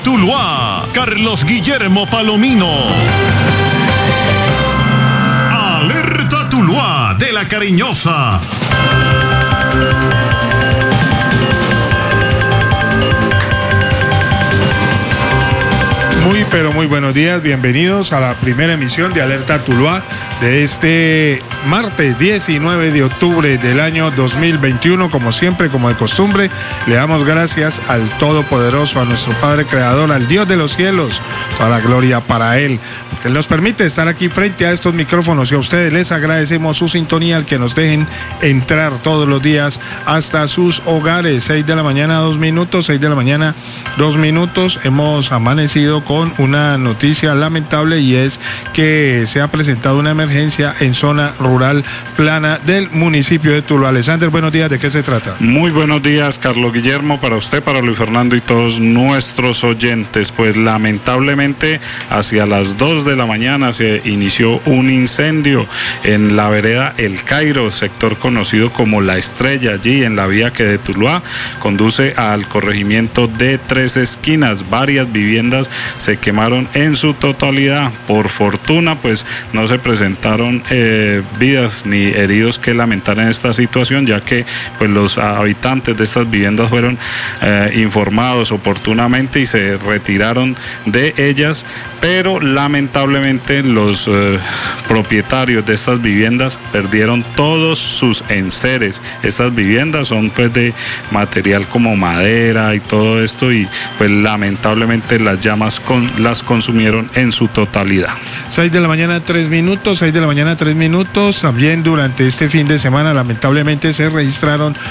Radio
Abren la emisión del informativo con el incendio en zona rural plana de Tuluá, en el asentamiento conocido como La Estrella en la vereda el Cairo, la emergencia dejó cuatro casas incineradas.